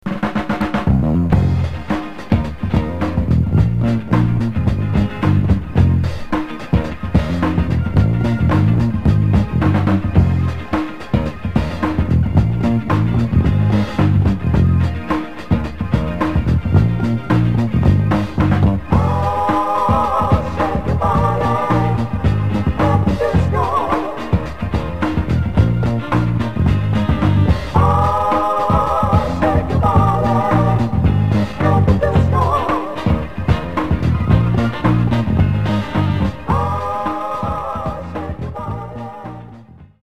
Genre: Soul/Funk